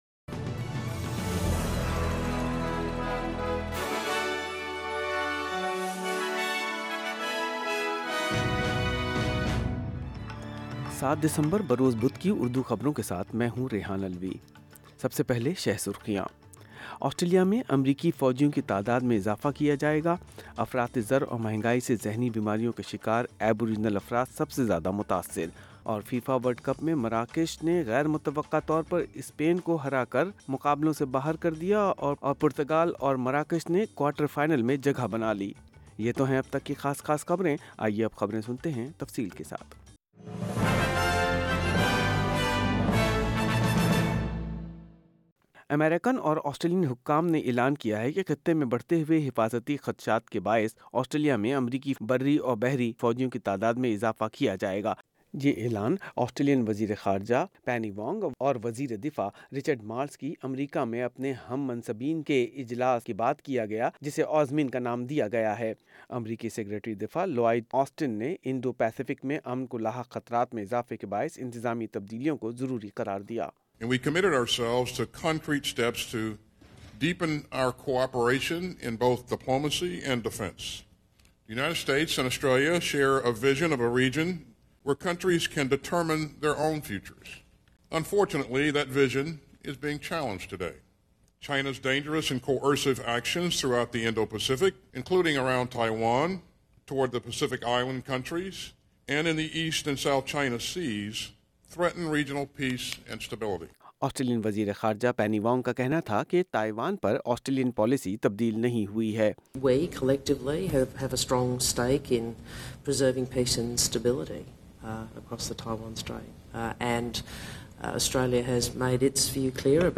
Urdu News Wednesday 7 December 2022